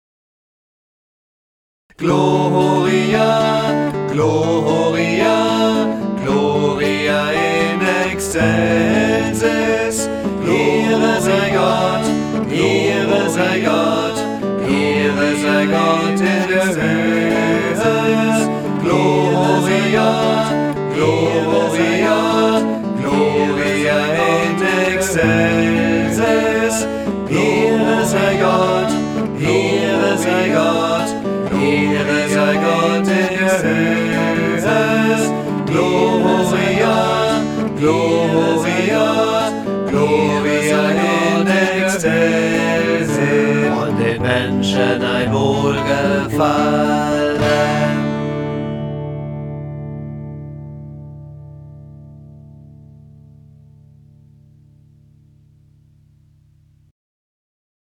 Audio Kanon (0:57)Herunterladen